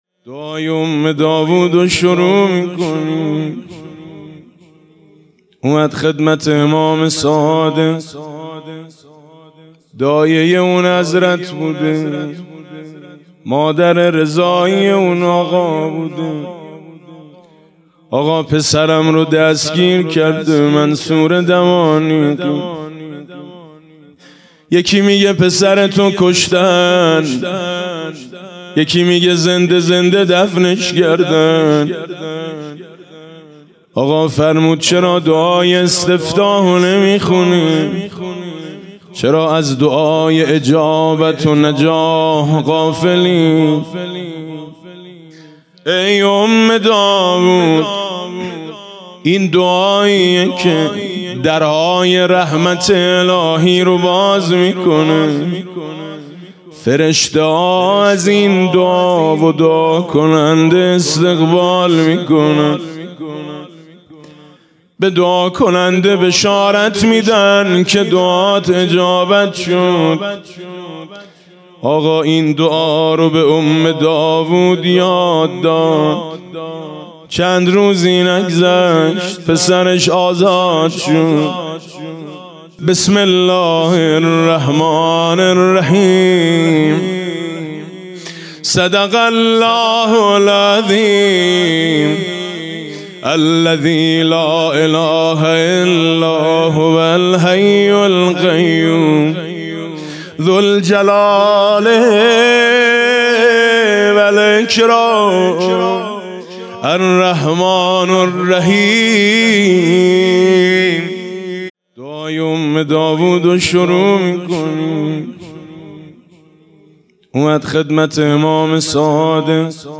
دانلود دعای ام داوود با صدای میثم مطیعی